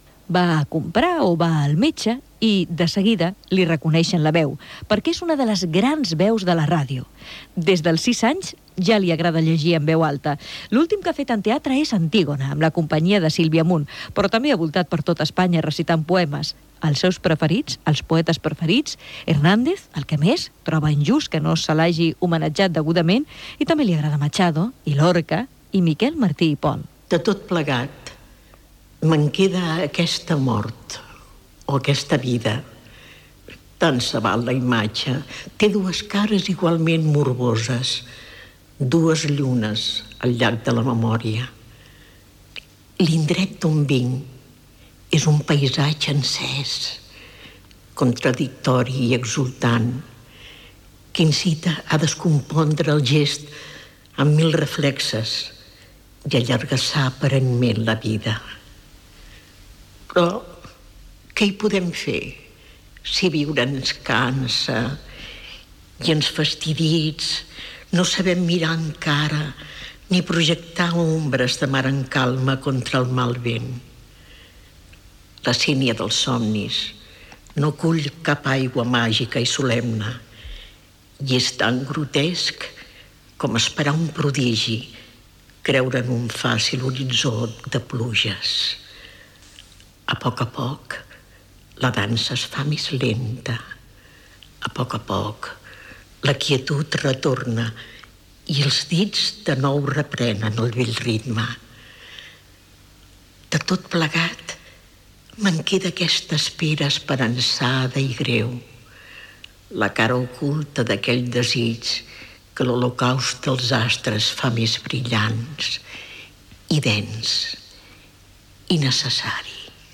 L'actriu Encarna Sánchez recorda la seva trajectòria a la ràdio.
Divulgació